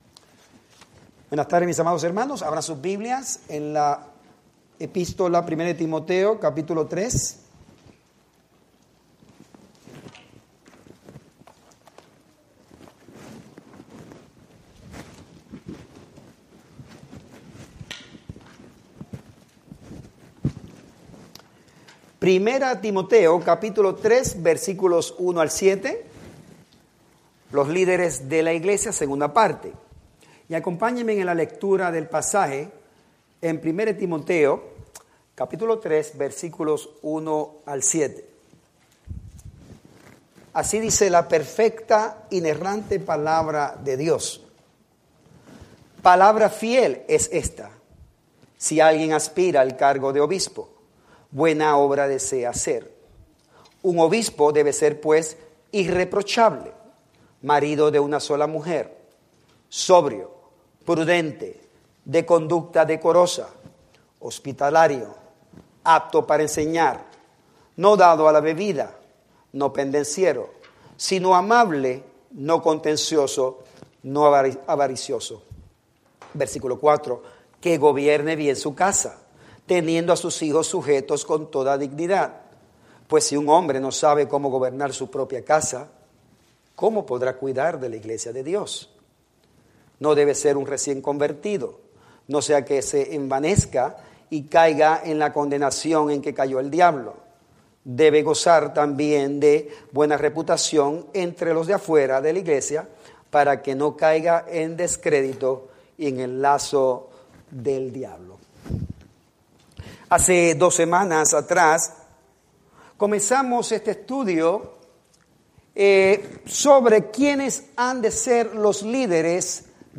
Spanish Bible Study